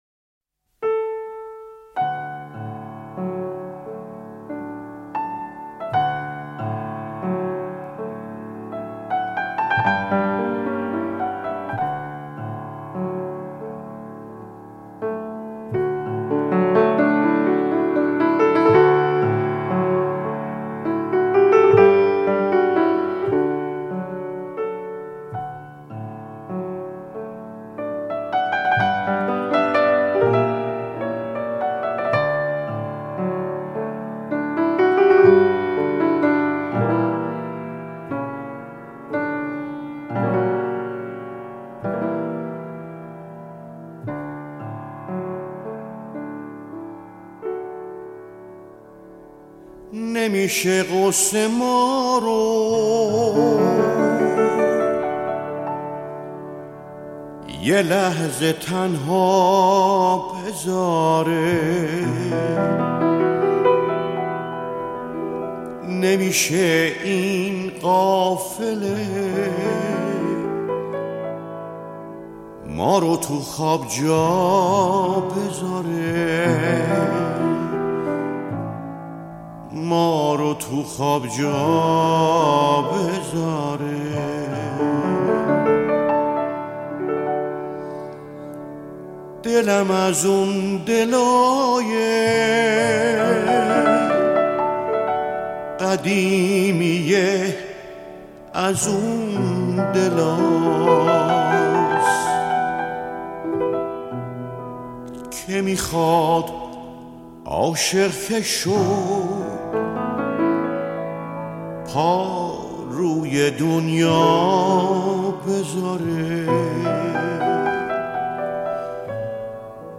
موسیقی